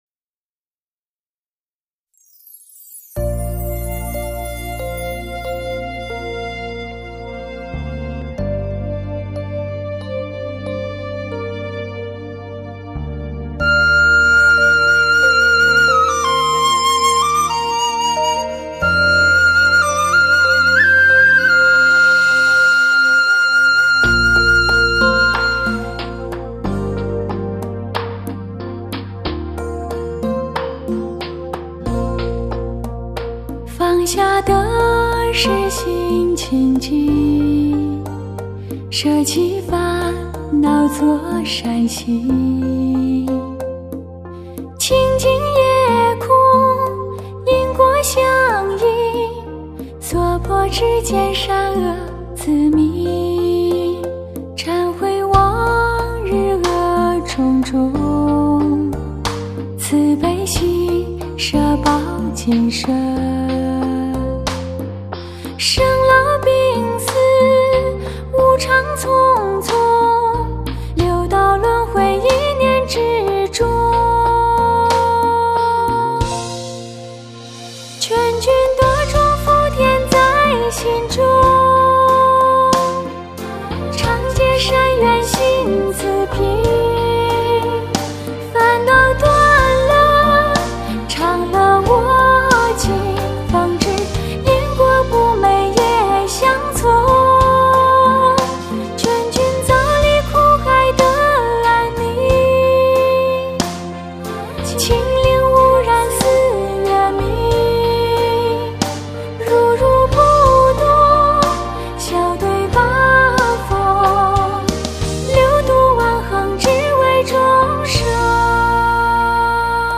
类型: 梵音佛语